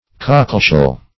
Cockleshell \Coc"kle*shell`\, n.